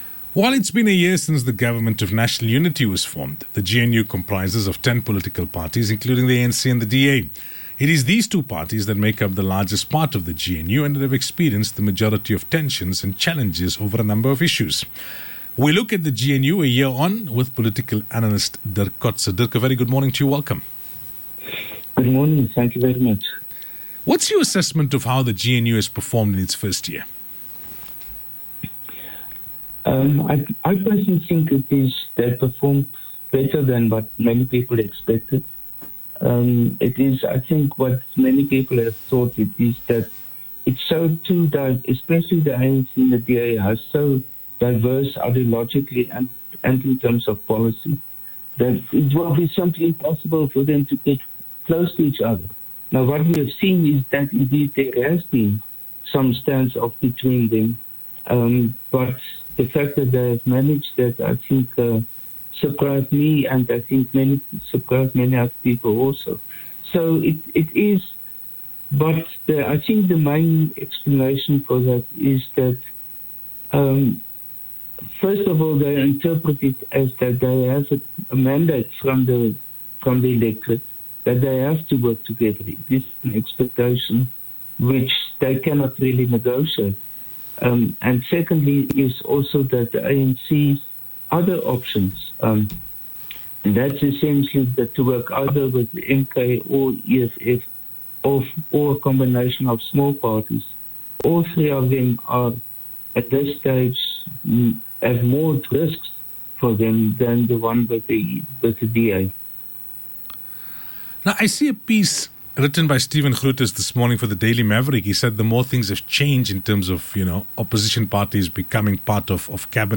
full interview here.